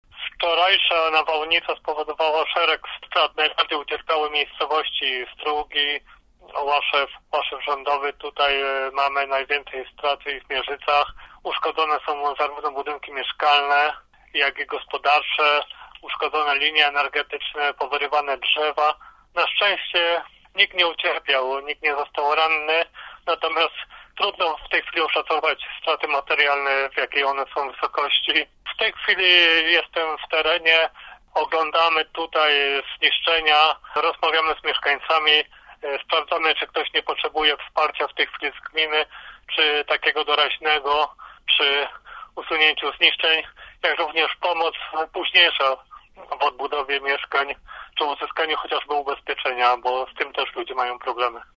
Uszkodzone są zarówno budynki mieszkalne jak i gospodarcze – mówi wójt gminy Leszek Gierczyk – Uszkodzone są linie energetyczne i powyrywane drzewa.